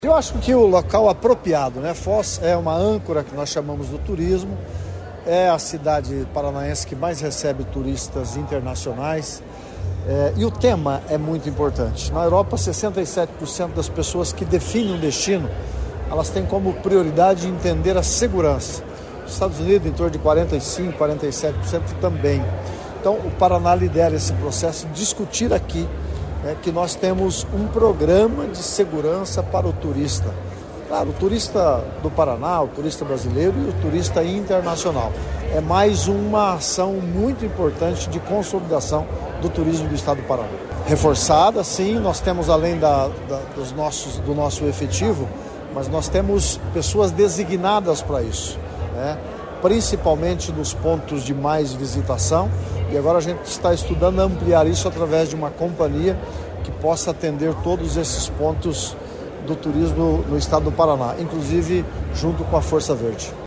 Sonora do secretário Estadual do Turismo, Leonaldo Paranhos, sobre o Simpósio Nacional de Segurança no Atendimento ao Turista